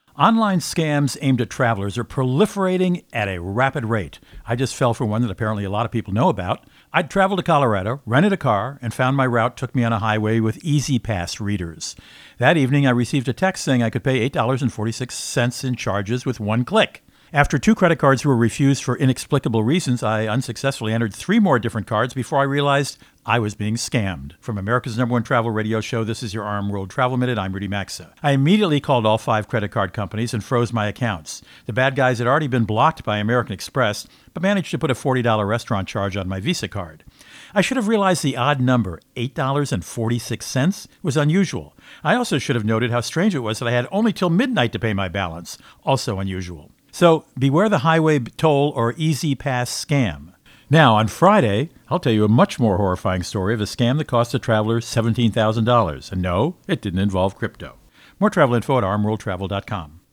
America's #1 Travel Radio Show
Co-Host Rudy Maxa | A Travel Scam and $17K